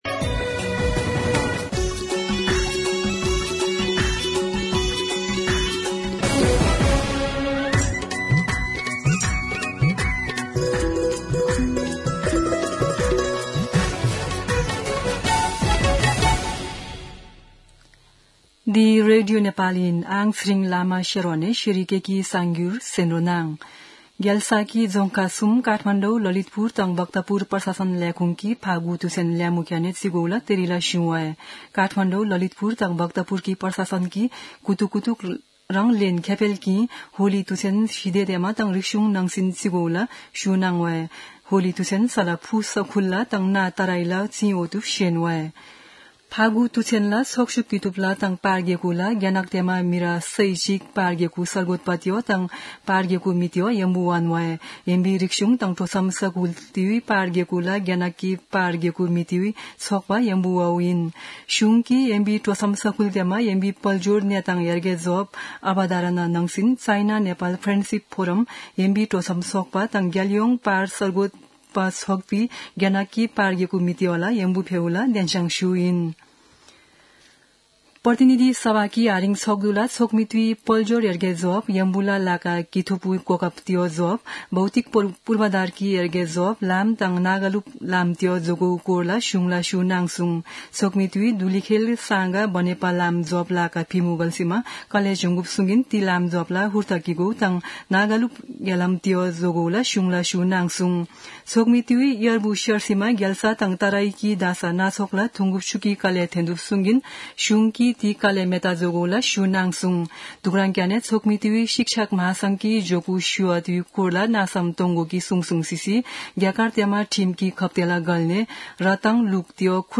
शेर्पा भाषाको समाचार : २९ फागुन , २०८१
Sherpa-News-11-28.mp3